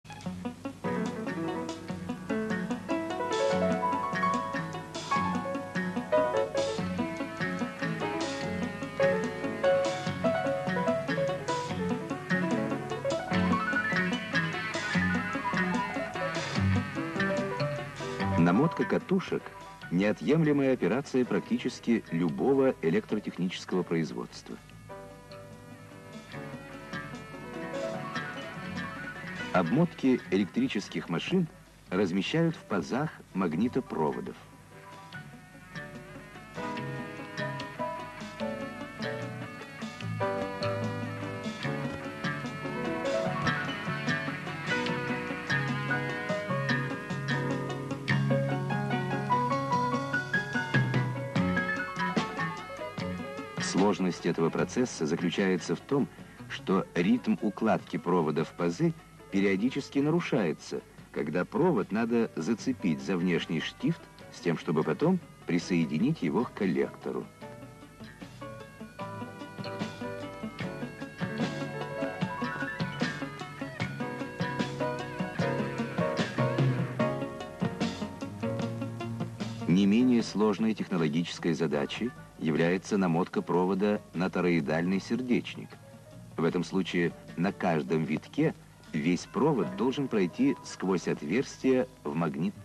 Да. Автор этой пьесы- Овсянников, но оркестр не его
Этот фрагмент я вырезал из учебного фильма 1981г.